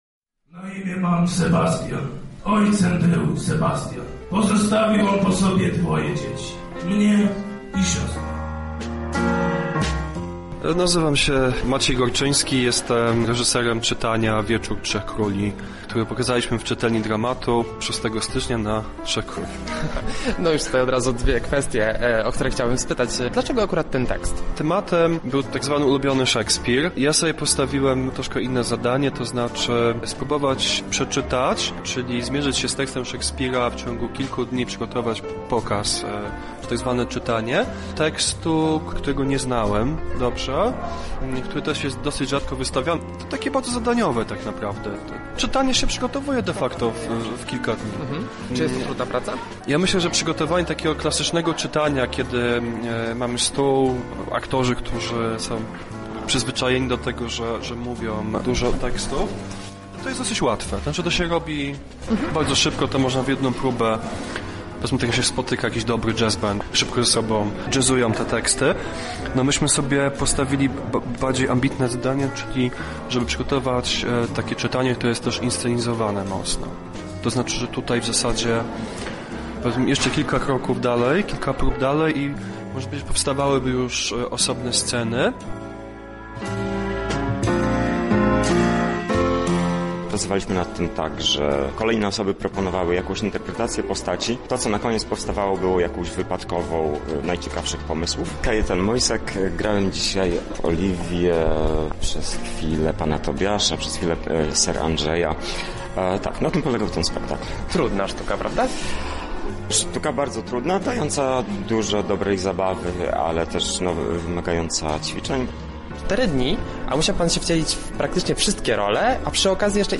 Relację z wydarzenia